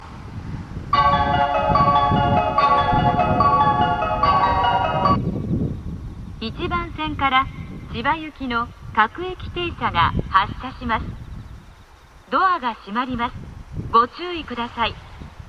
スピーカーは小ボスとユニペックス箱型が使用されていますが小ボスのほうが音質がいいのでそちらでの収録を 進めます。
１番線内房線
発車メロディー途中切りです。